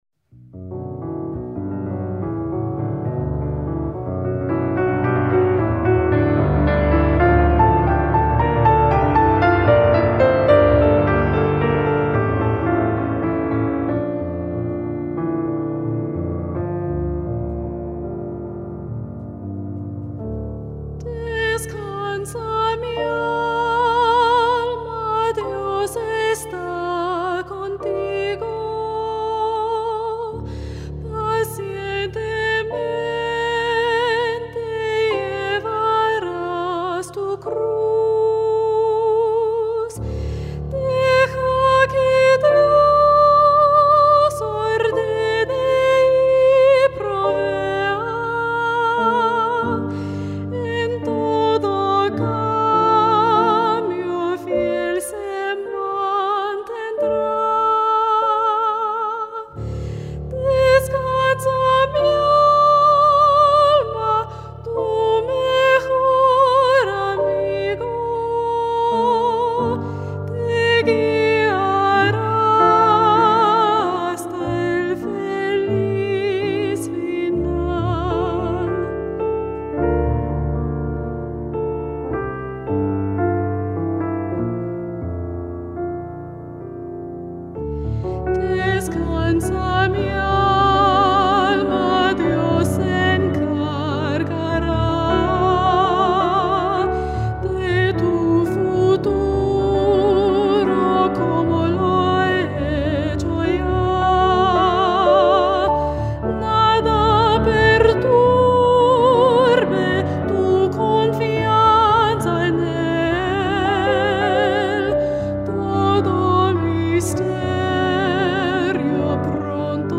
Soprano and Piano
Hymn arrangement.